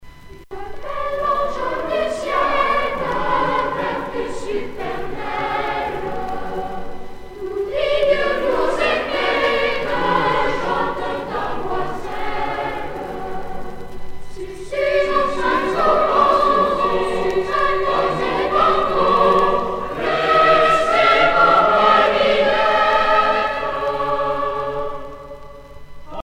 circonstance : Noël, Nativité